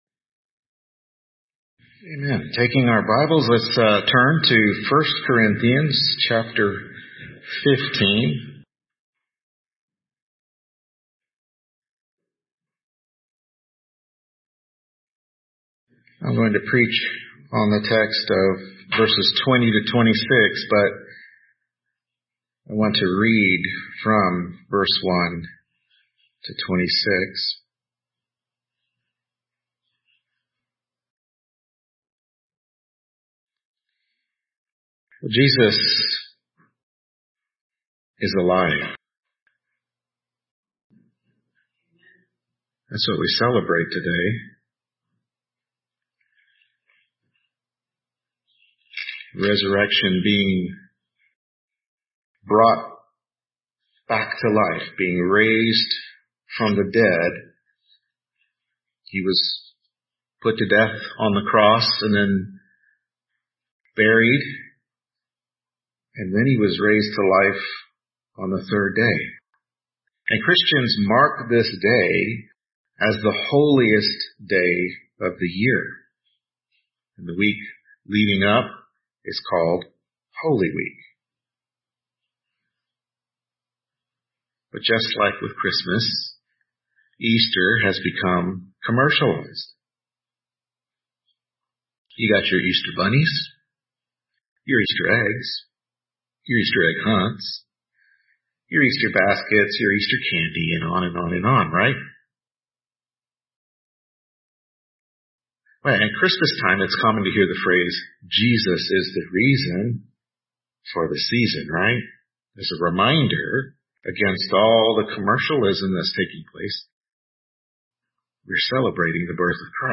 1 Corinthians 15:20-26 Service Type: Morning Worship Service Easter 2023 / Sunrise Service 1 Corinthians 15:20-26 Resurrection is Fact!